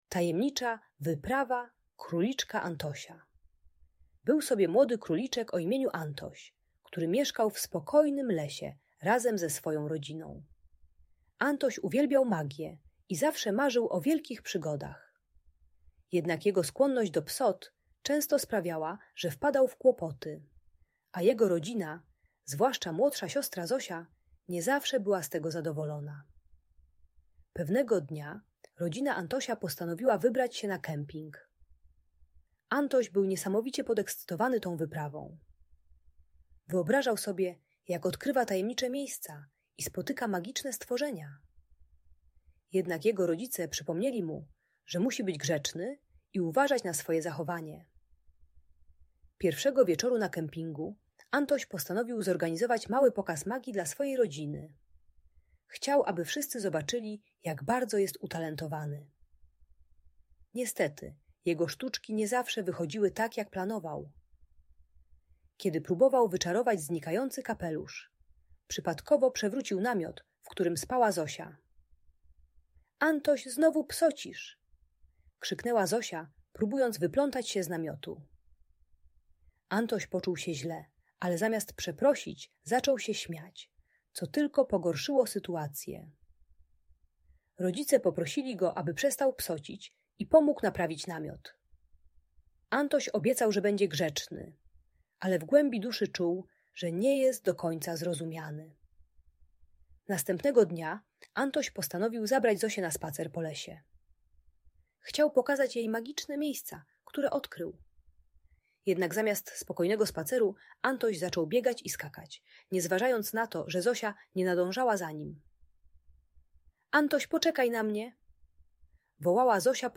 Tajemnicza wyprawa Króliczka Antosia - Bunt i wybuchy złości | Audiobajka